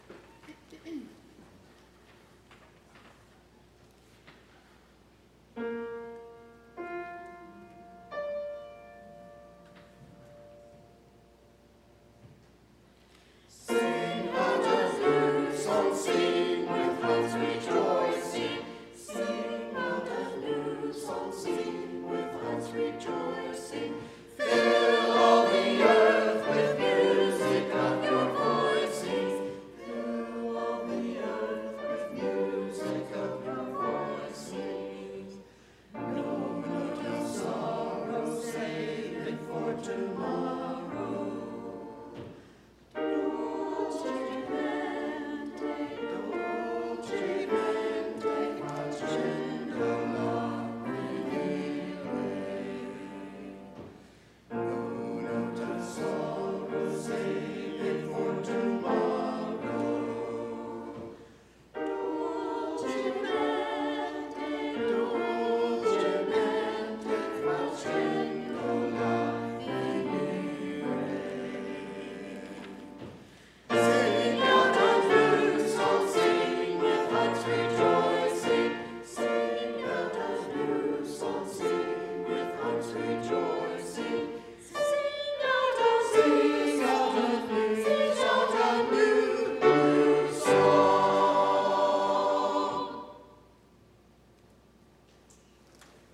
anthem_9_8_19.mp3